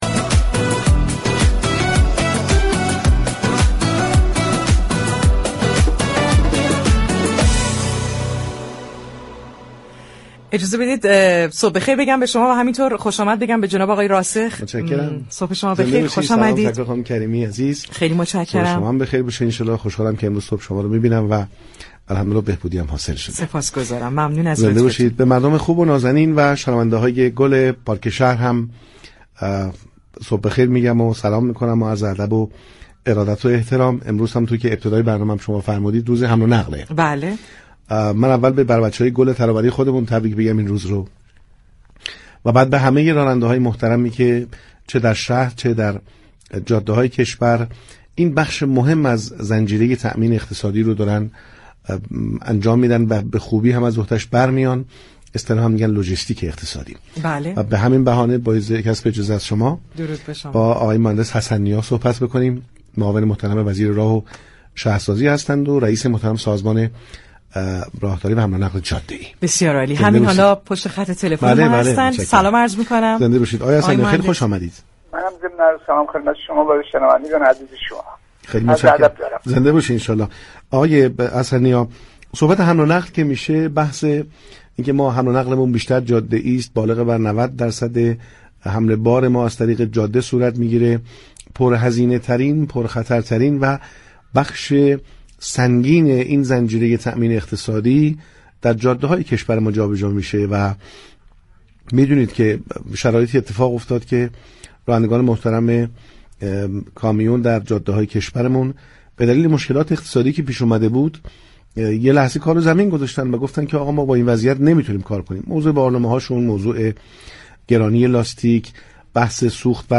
عبدالهاشم حسن‌نیا معاون وزیر راه و شهرسازی و رئیس سازمان راهداری و حمل و نقل جاده‌ای كشور در گفتگو با پارك شهر گفتگو درباره حمل و نقل جاده‌ای توضیح داد.